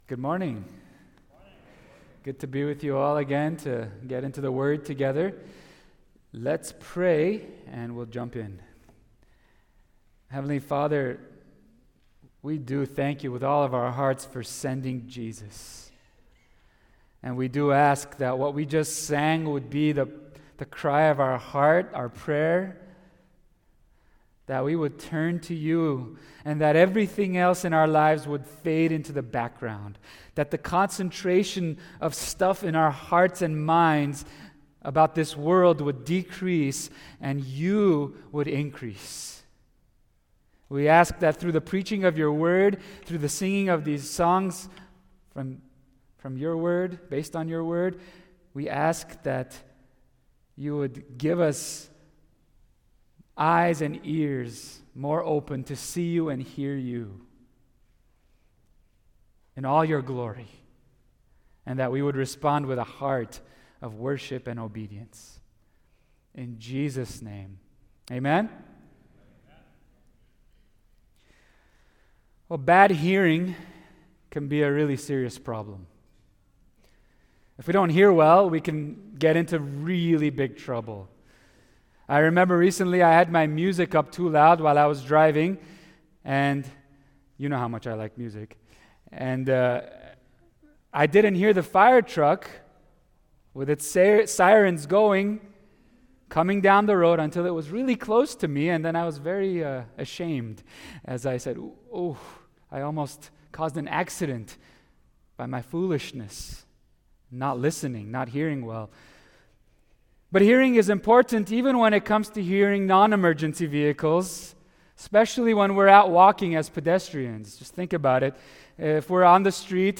Sermon on Luke 16:19-31
Service Type: Sunday Service